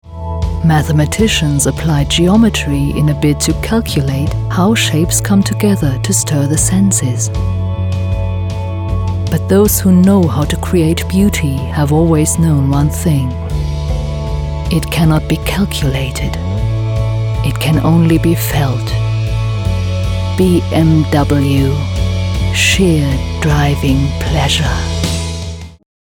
Mittel minus (25-45)
Ruhrgebiet
Eigene Sprecherkabine
Commercial (Werbung)